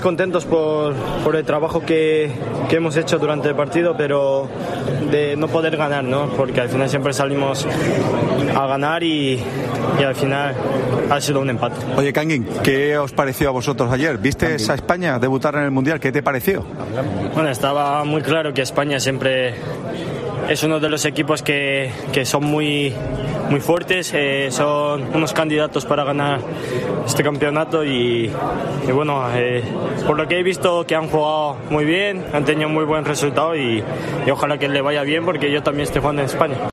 El jugador del RCD Mallorca ante el micrófono de Cope